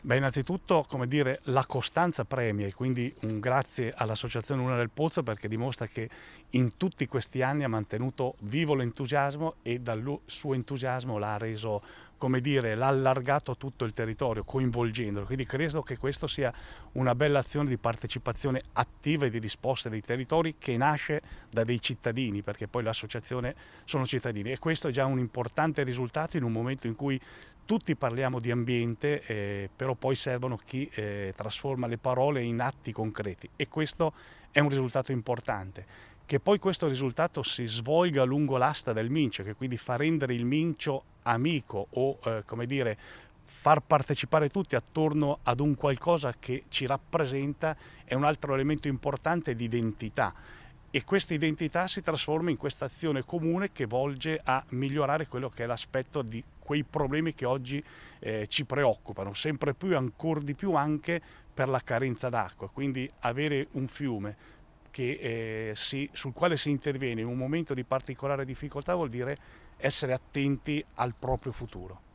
Maurizio Pellizzer, Presidente Parco del Mincio